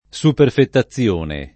superfetazione [ S uperfeta ZZL1 ne ]